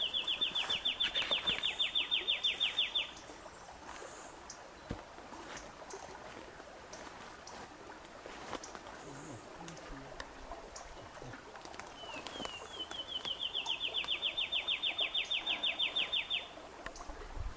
Pula-pula-ribeirinho (Myiothlypis rivularis)
Nome em Inglês: Riverbank Warbler
Fase da vida: Adulto
Localidade ou área protegida: Parque Provincial Urugua-í
Condição: Selvagem
Certeza: Fotografado, Gravado Vocal